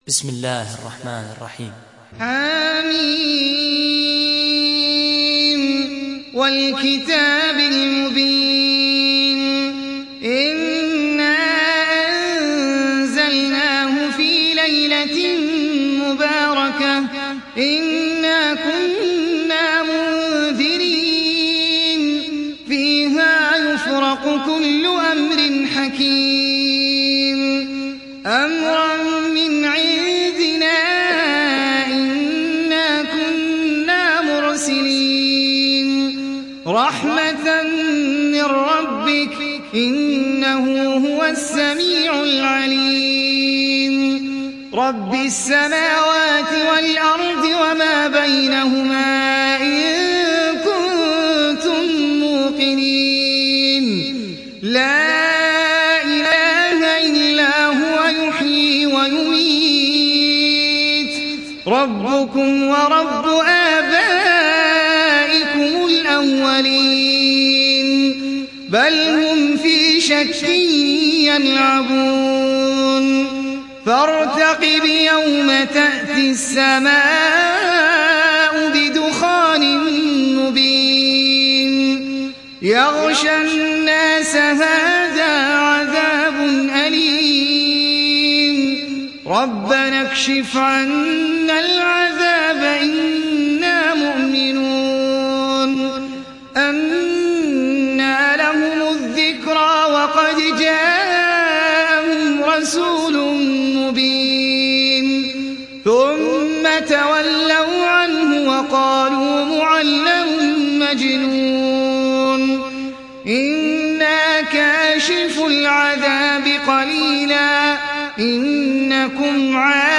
Surah Ad Dukhan Download mp3 Ahmed Al Ajmi Riwayat Hafs from Asim, Download Quran and listen mp3 full direct links
Download Surah Ad Dukhan Ahmed Al Ajmi